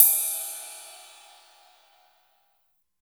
16 CYMBAL.wav